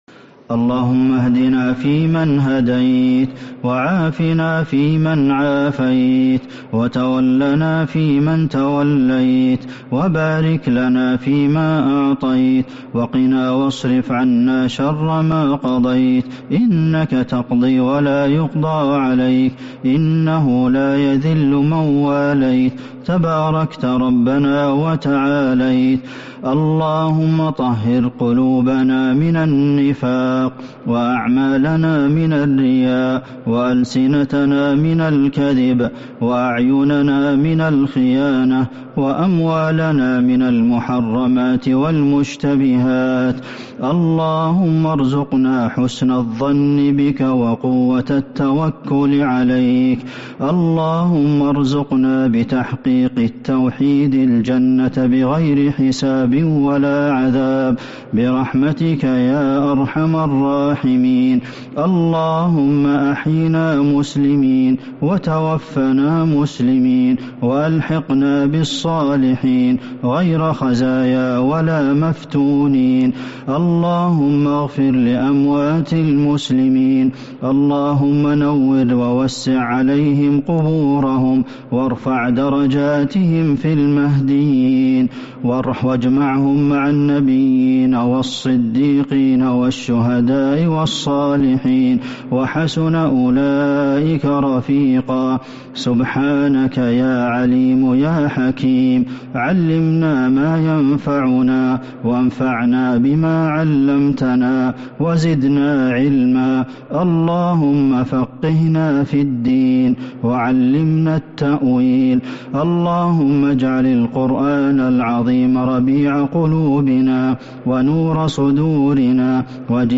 دعاء ليلة 5 رمضان 1443هـ | Dua for the night of 5 Ramadan 1443H > تراويح الحرم النبوي عام 1443 🕌 > التراويح - تلاوات الحرمين